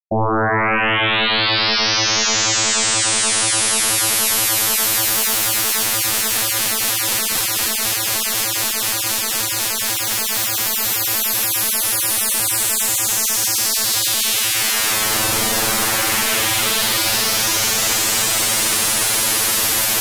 IKONKA GŁOśNIKA Przykład ciągłej zmiany indeksu modulacji przy częstotliwości podstawowej 440Hz i modulującej 110 Hz